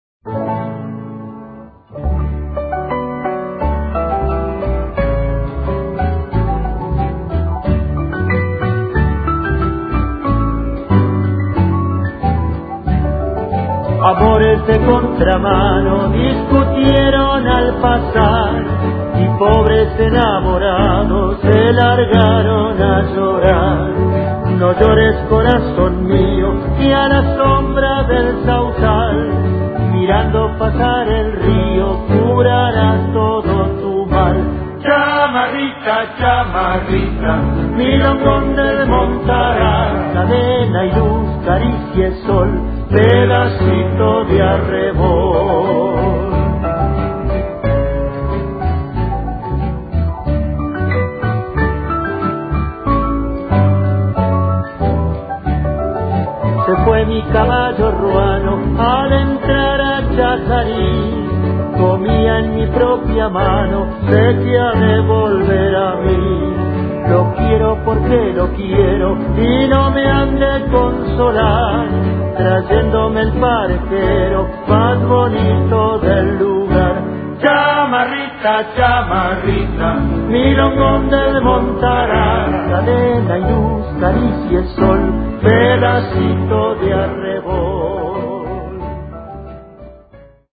DANZAS FOLKLÓRICAS TRADICIONALES ARGENTINAS
Hay evidencias de que se bailó esporádicamente en salones entrerrianos y santafesinos desde 1855, aunque su popularización se habría producido diez años después, durante la guerra, por el paso de los soldados brasileños que dejó al menos las coplas y tal vez las melodías en la campaña entrerriana y correntina, donde también se la llamó polka canaria ya que se trataría de una variedad de polka popularizada en las Islas Canarias, Azores y Madeira.
bajar la música de la chamarrita; interpretada por Danzas Tradicionales